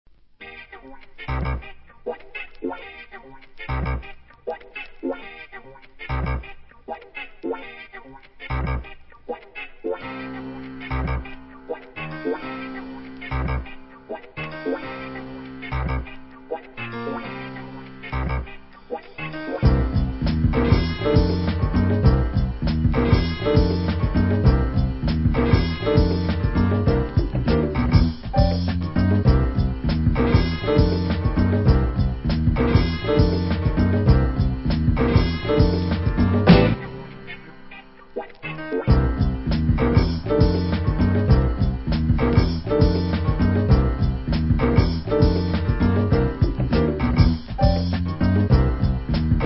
HIP HOP/R&B
1993年、JAZZY GROOVEブレイク物!!